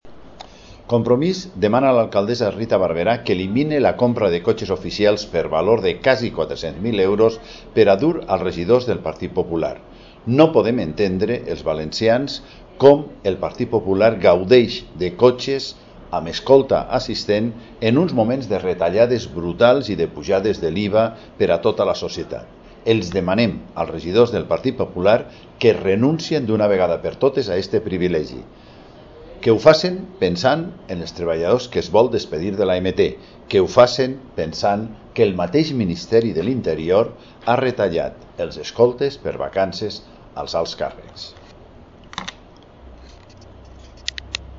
Declaraciones de Joan Ribó reclamando la cancelación inmediata de los coches oficiales de uso personal (en valencià)